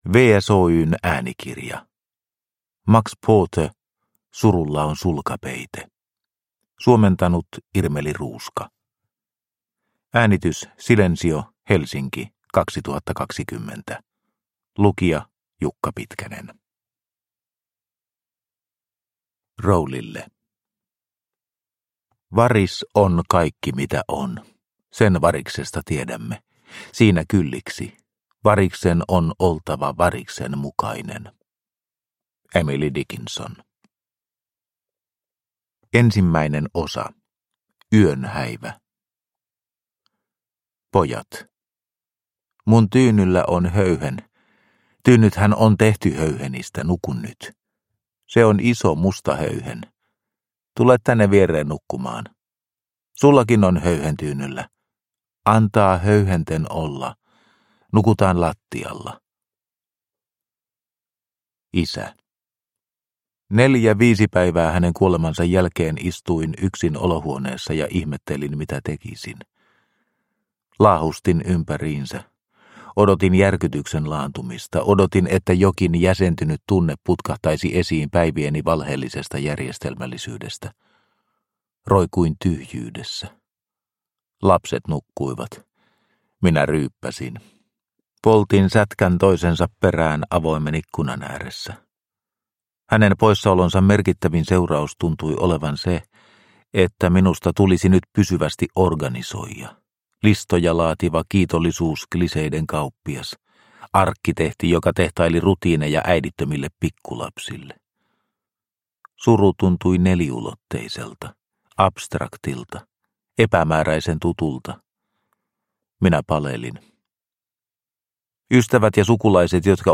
Surulla on sulkapeite – Ljudbok – Laddas ner